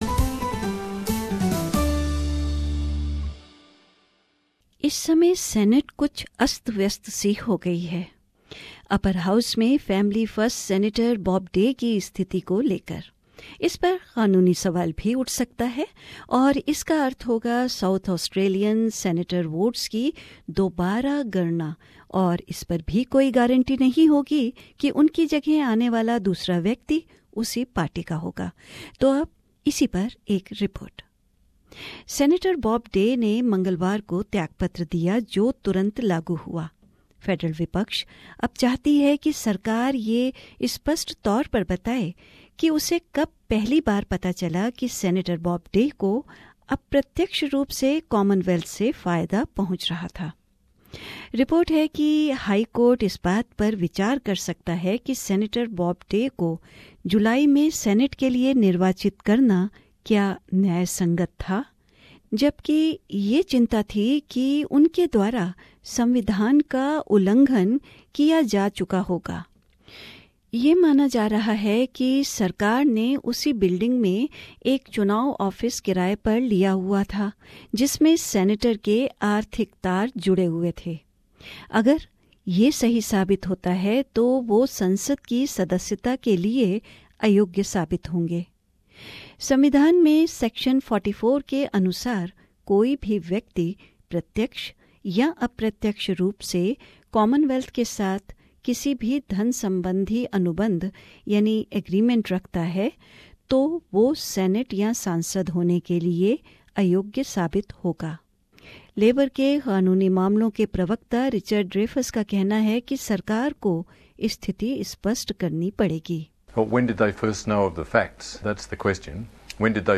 एक फीचर...